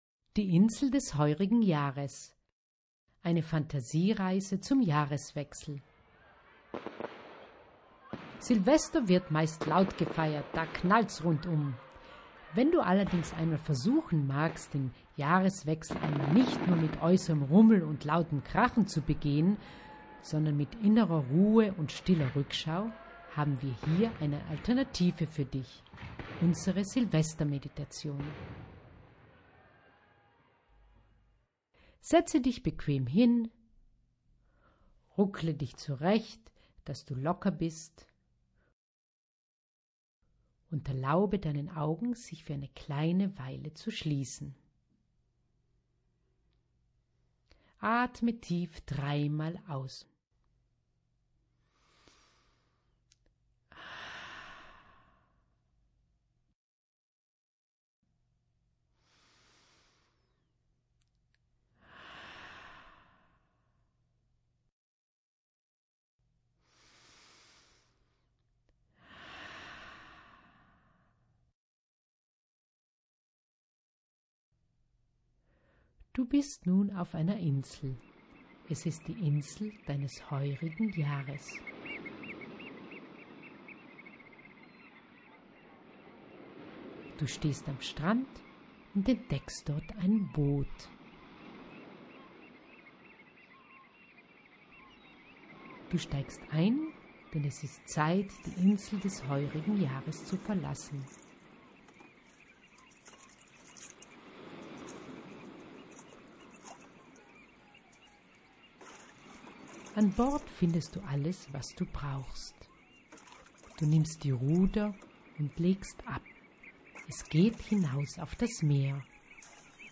Meditation: Die Insel des heurigen Jahres - Telos Training Bozen
Sprache und Hintergrundgeräusche
06-6-Silvestermeditation.mp3